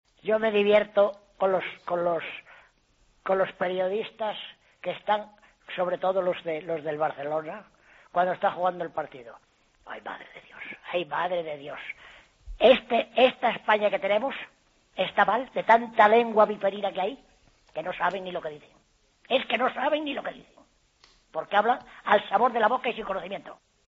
El oyente enfurecido